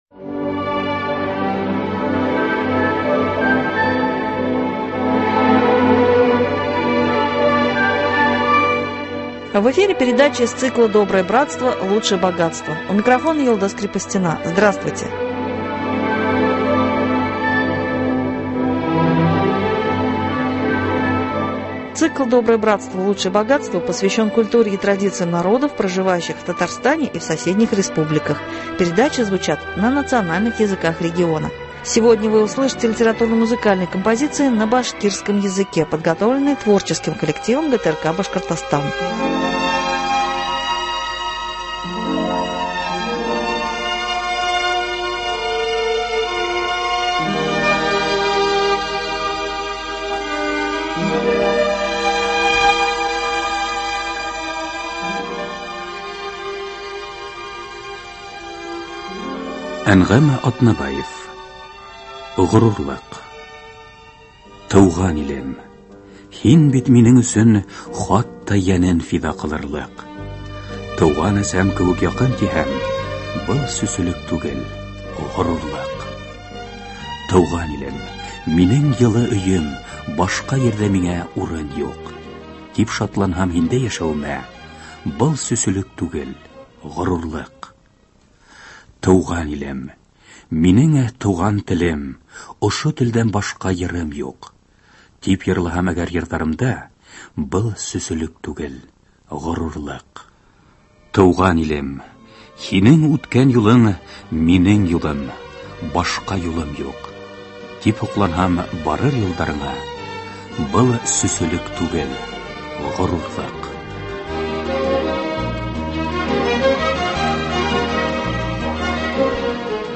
Цикл посвящен культуре и традициям народов, проживающих в Татарстане и соседних республиках, передачи звучат на национальных языках региона . Сегодня вы услышите литературно- музыкальные композиции на башкирском языке, подготовленные творческим коллективом ГТРК Башкортостан.